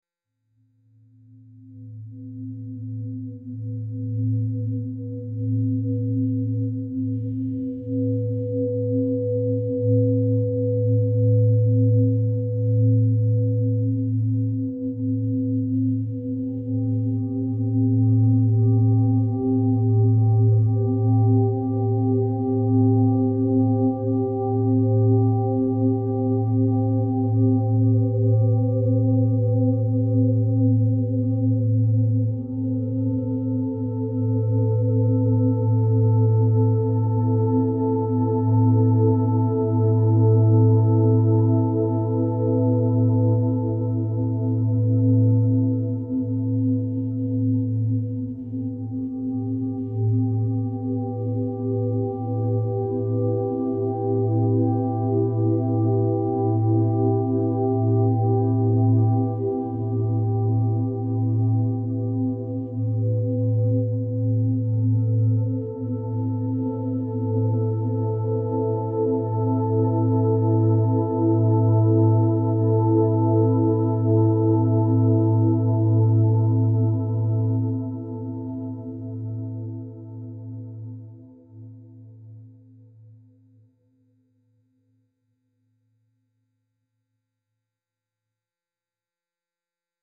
deep resonant singing bowls with ambient drones
deep resonant singing bowls with ambient drones and heavenly pads